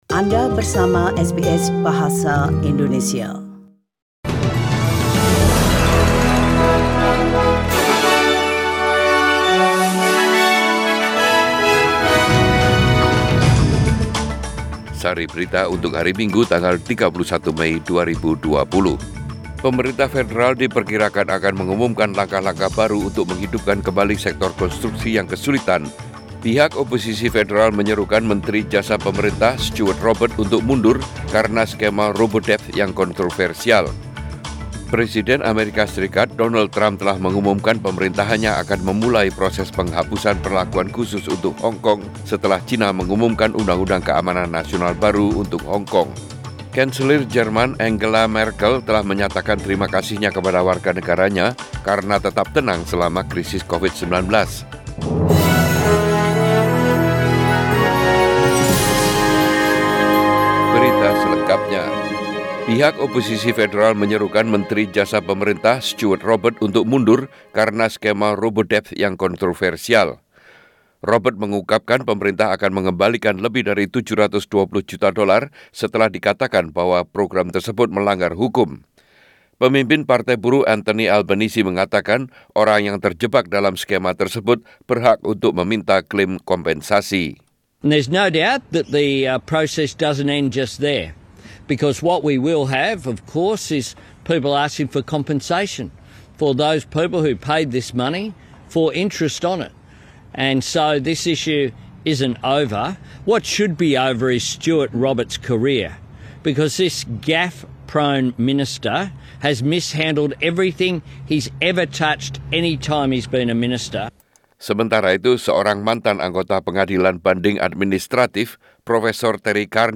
SBS Radio News in Bahasa Indonesia Bahasa Indonesia - 31 May 2020